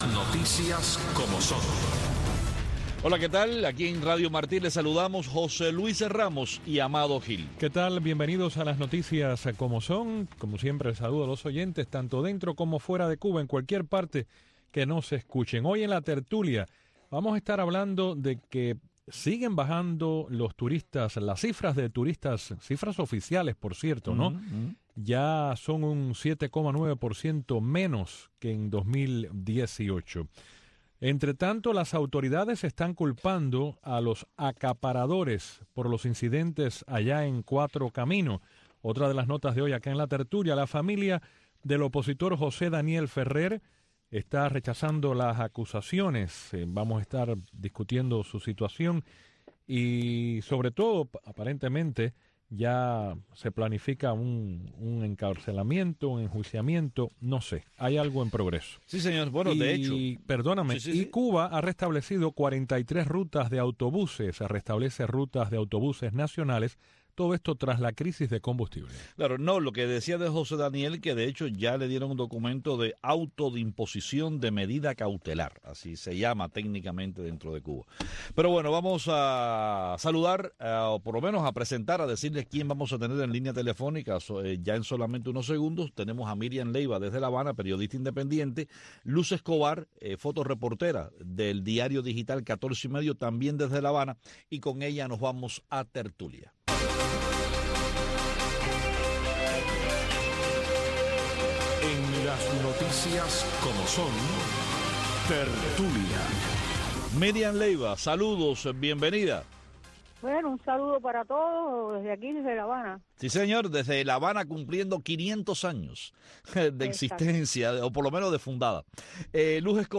Conversamos tambien con periodistas en Bolivia y Nicaragua sobre la tensa situación que viven ambos países.